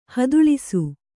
♪ haduḷisu